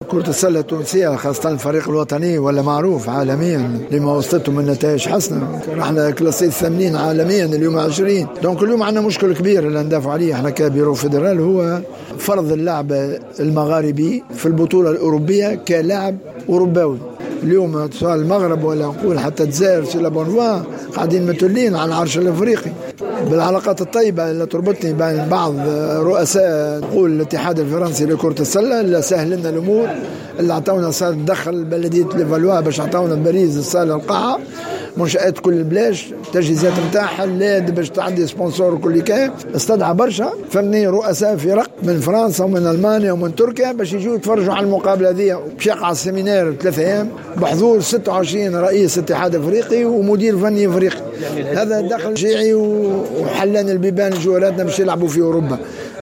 عقدت الجامعة التونسية لكرة السلة ندوة صحفية صباح اليوم السبت 8 فيفري 2020 بالعاصمة...